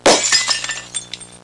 Breaking Glass Sound Effect
Download a high-quality breaking glass sound effect.
breaking-glass-1.mp3